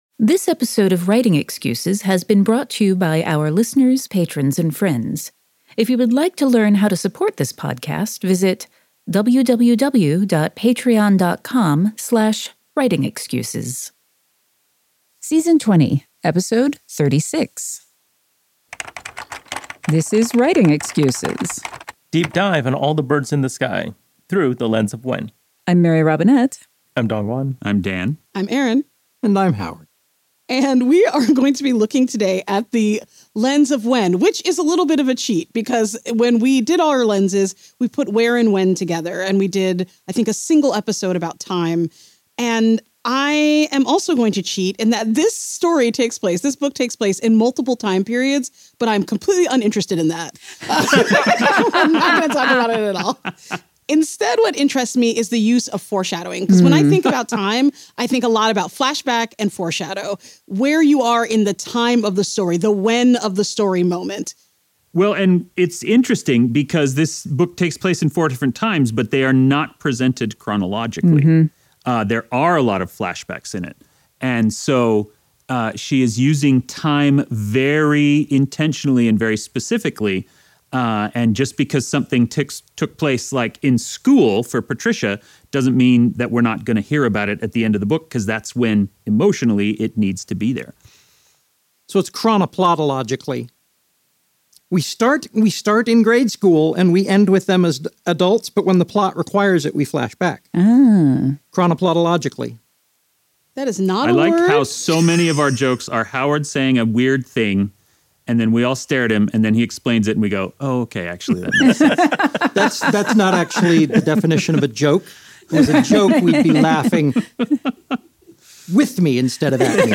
If there's a crowd with good questions, it's the Out of Excuses Workshop and Retreat attendees. Given the trend toward moral ambiguity, is there still a place for an unquestionably evil character?